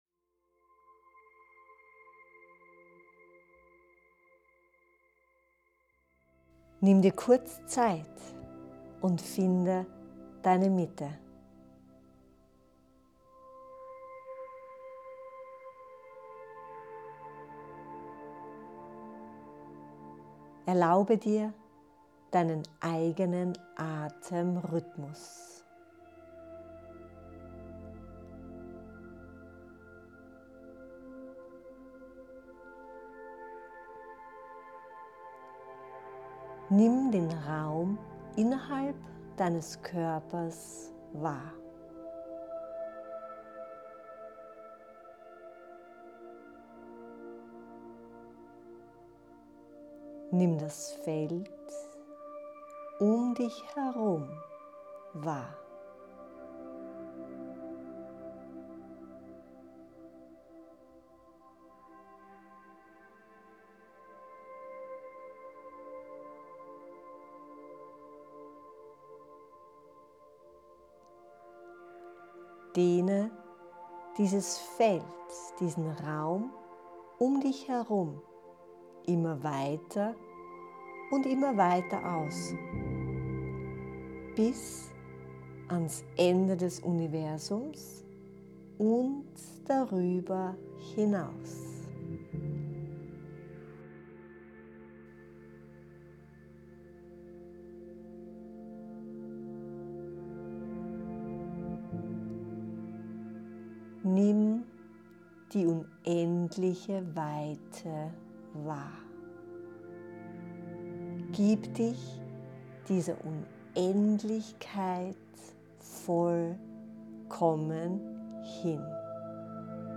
Abendmeditation